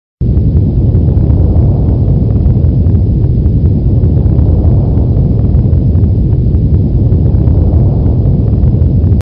دانلود آهنگ زمین لرزه 3 از افکت صوتی طبیعت و محیط
دانلود صدای زمین لرزه 3 از ساعد نیوز با لینک مستقیم و کیفیت بالا
جلوه های صوتی